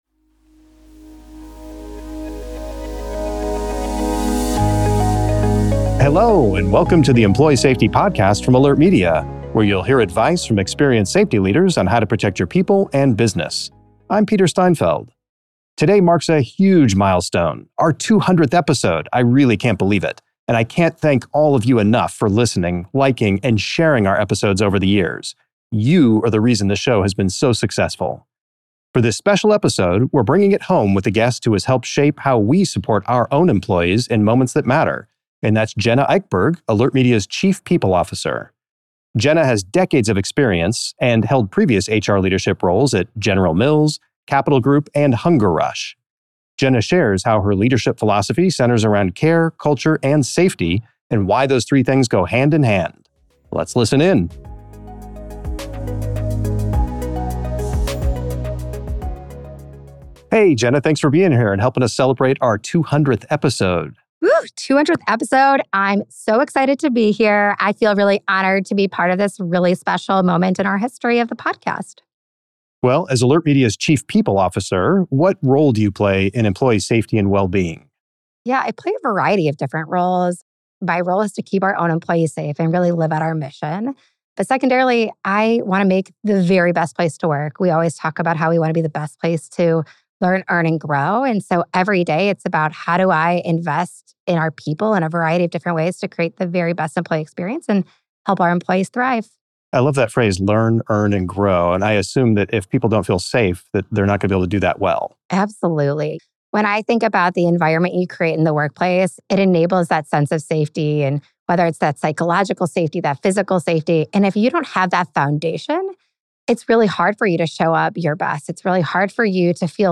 You can find this interview and many more by following The Employee Safety Podcast on Spotify or Apple Podcasts .